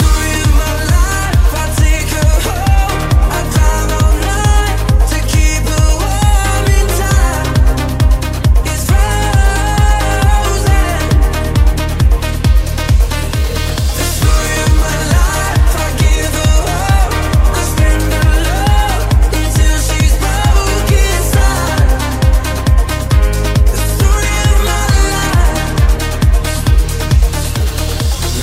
Genere: afrobeat,afrohouse,deep,remix,hit